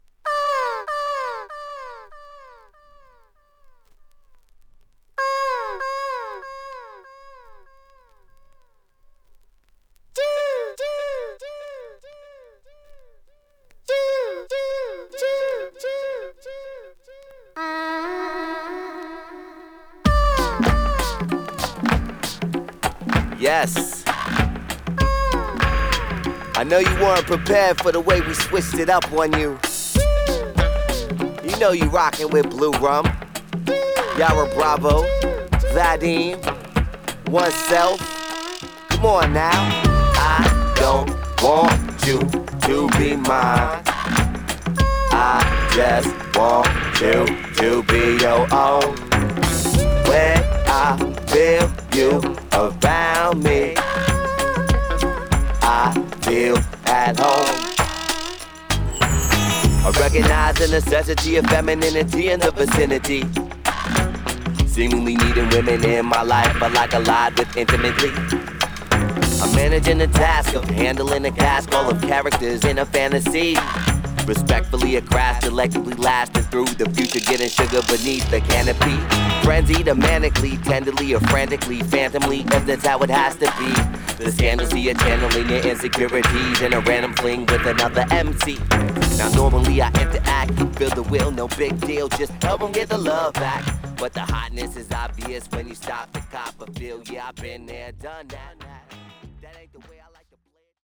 アラブ風コーラスとパーカッションがエスニックな雰囲気を盛り上げ